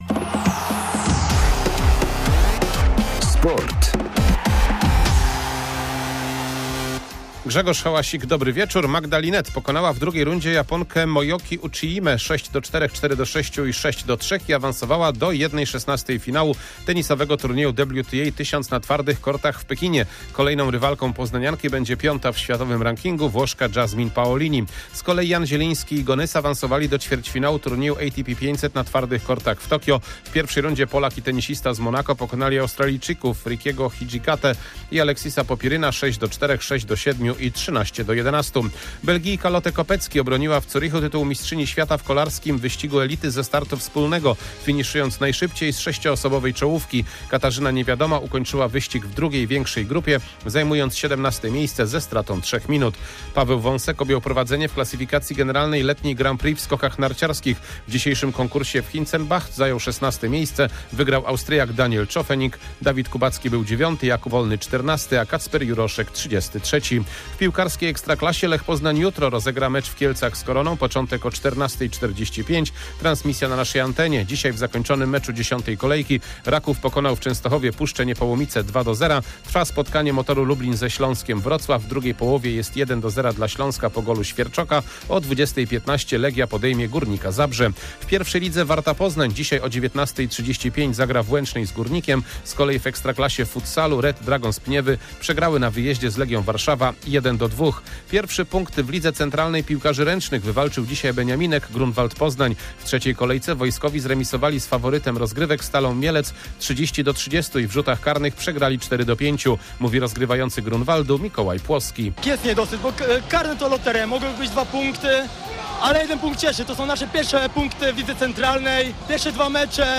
28.09.2024 SERWIS SPORTOWY GODZ. 19:05